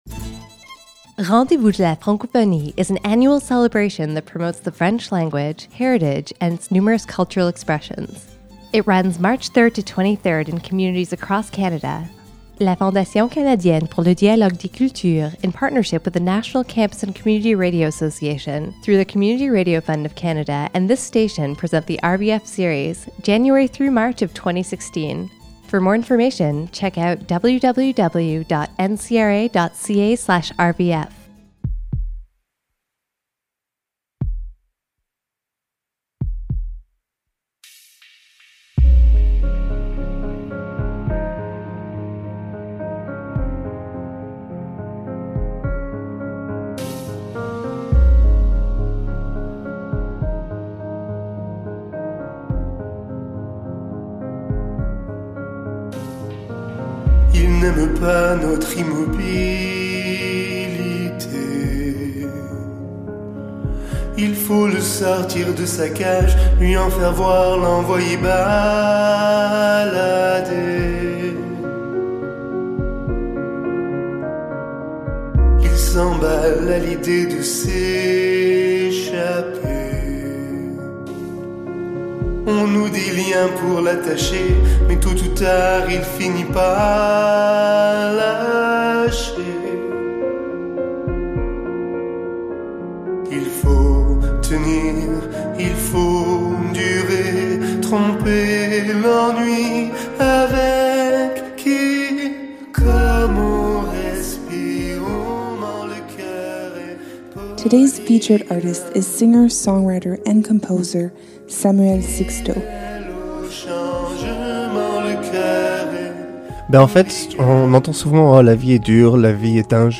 Capsules containing information, interviews, and music from different Western Canadian Francophone artists.